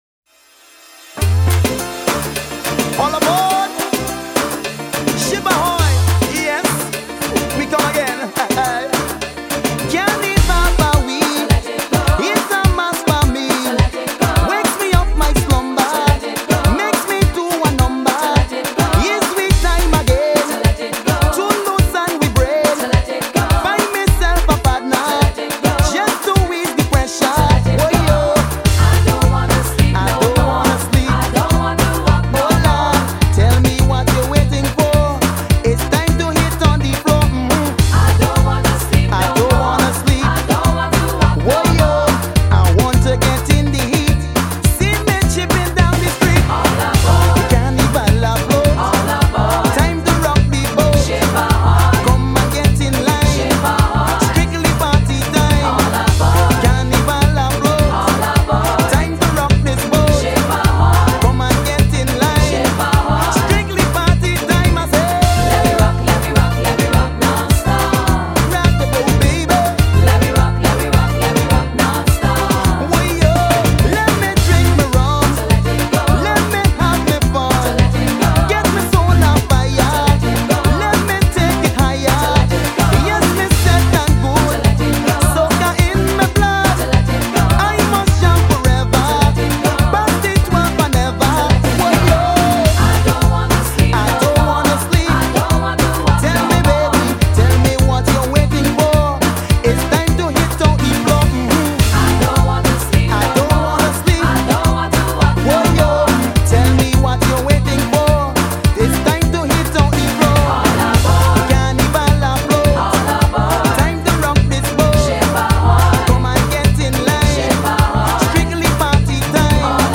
One of my favorite soca tunes–it’s got hooks aplenty.